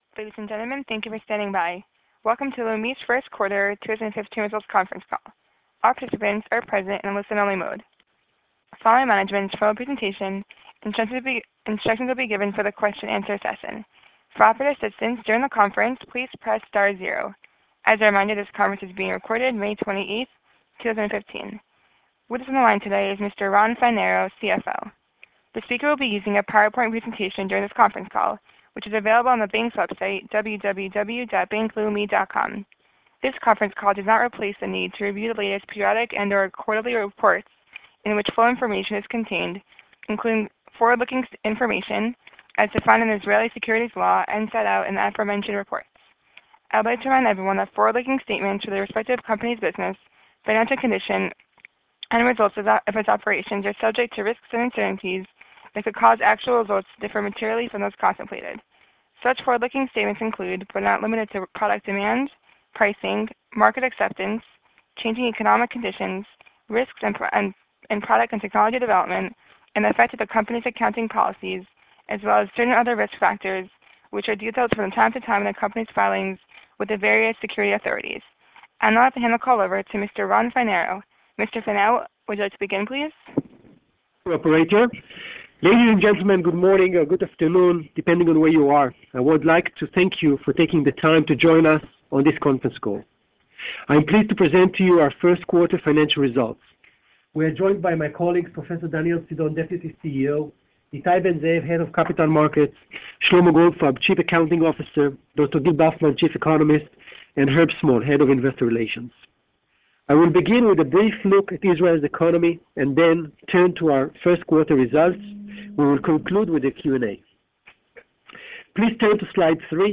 Investor Presentation and Conference Call